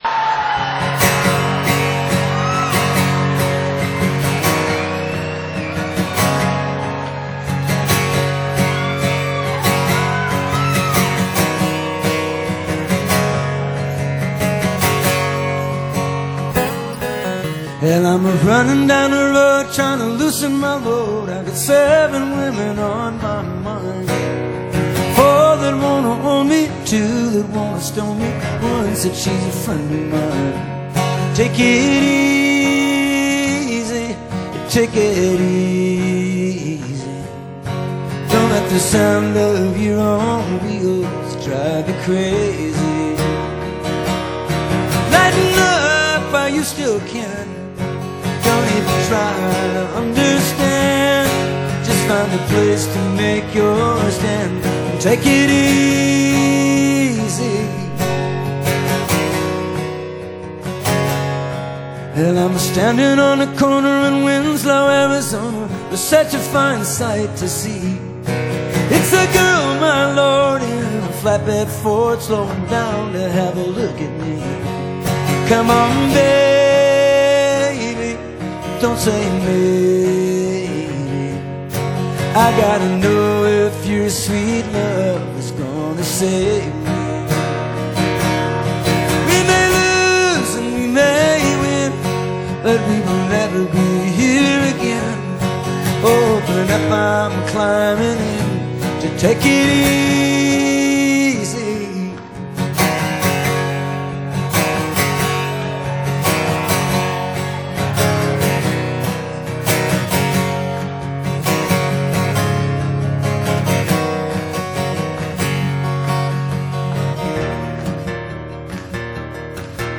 Gerne: Rock
以空心吉他和鋼琴完美呈現經典名曲Acoustic動人之全新風貌。